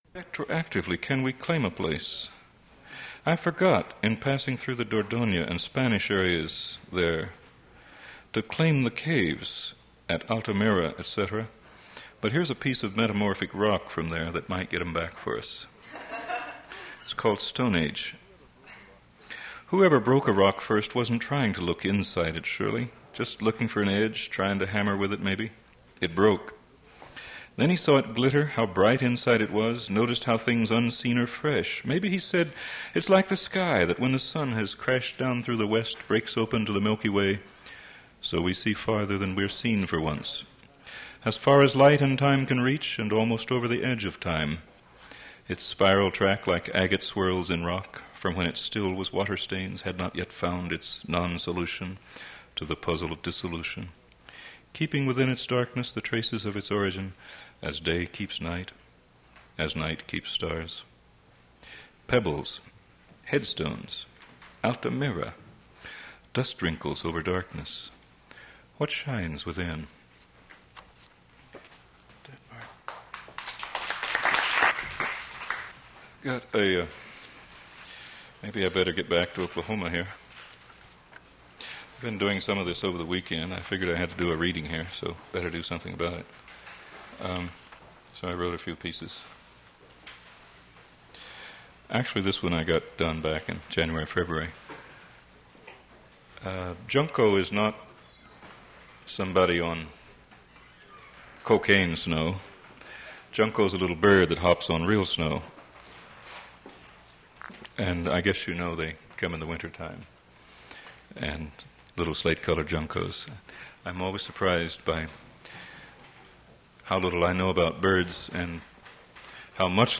Poetry reading featuring Carter Revard
Attributes Attribute Name Values Description Carter Revard poetry reading at Duff's Restaurant.
mp3 edited access file was created from unedited access file which was sourced from preservation WAV file that was generated from original audio cassette.